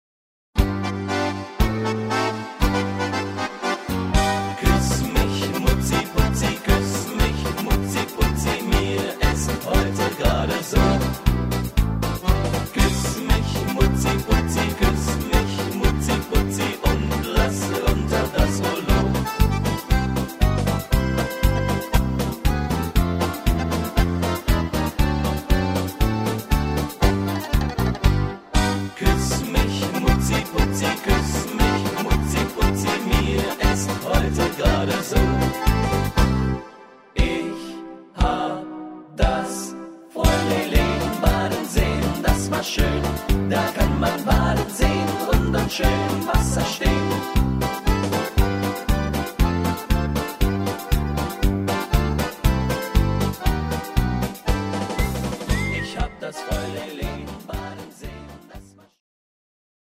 Rhythmus  Charleston